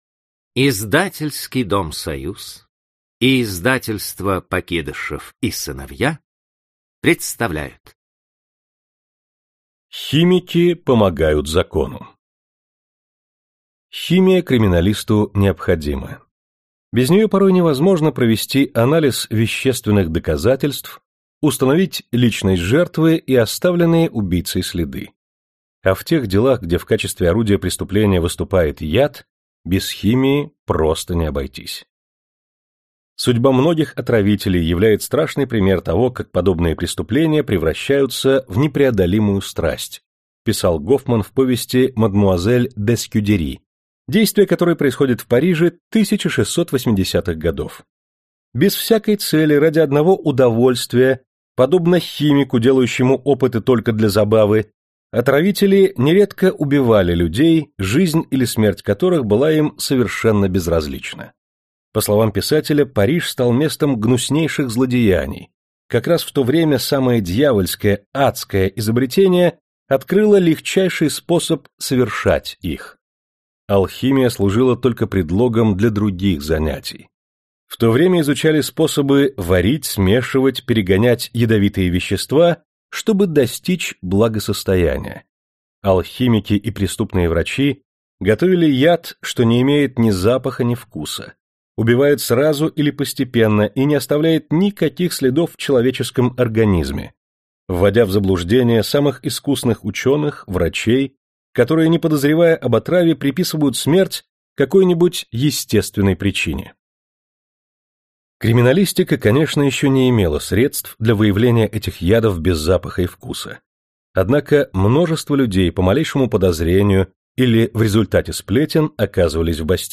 Аудиокнига Криминалистика. Прорыв в науке | Библиотека аудиокниг